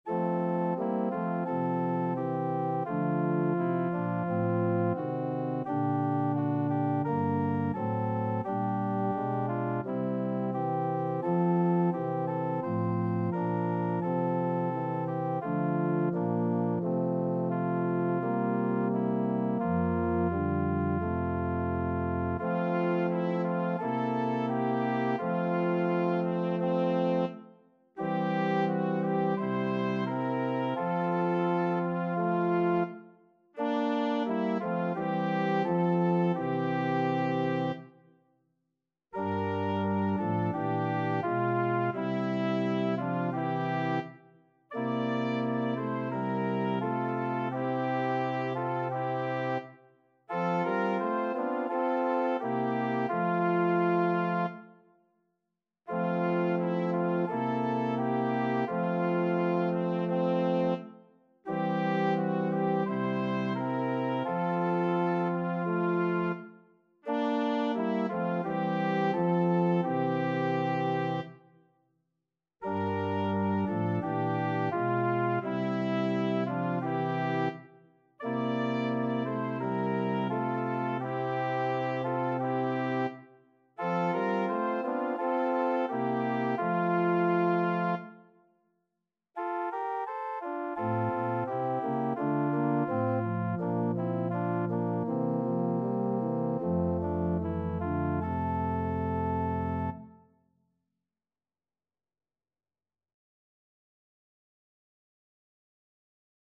Title: Vem, ó divino Espírito Composer: João Baptista Lehmann Lyricist: João Baptista Lehmann Number of voices: 1v Voicing: Unison Genre: Sacred, Sequence hymn
Language: Portuguese Instruments: Organ
HP_54_Vem,_ó_divino_Espírito_ÓRGÃO.mp3